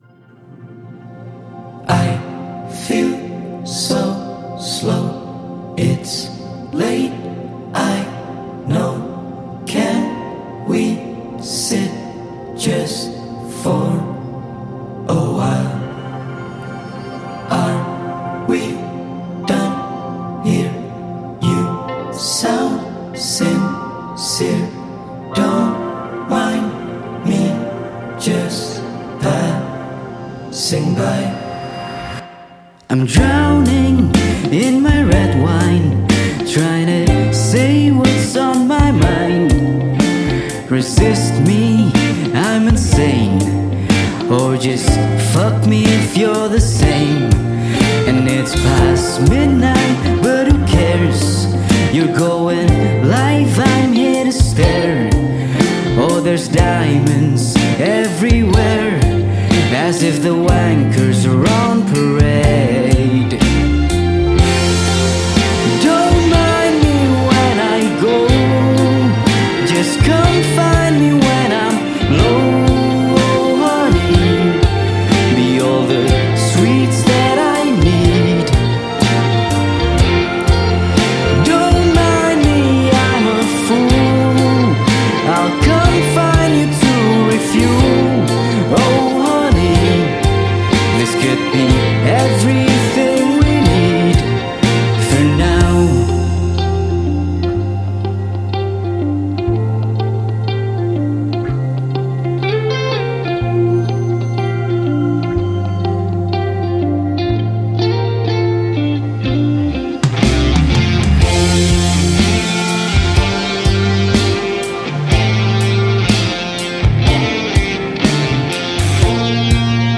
Alternative • Jakarta Timur